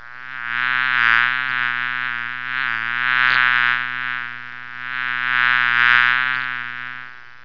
BUZZ.WAV